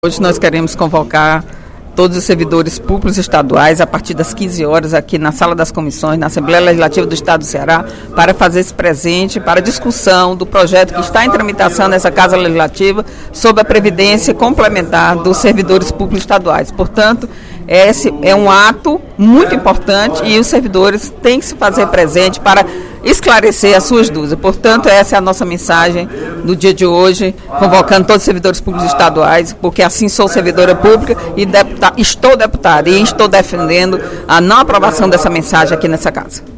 No primeiro expediente da sessão plenária desta quinta-feira (07/03), a deputada Eliane Novais (PSB) convidou os servidores públicos para, na tarde de hoje, na Assembleia Legislativa, debater com o secretário de Planejamento e Gestão, Eduardo Diogo, a mensagem do Governo Estadual n° 7.460, que está tramitando na Casa.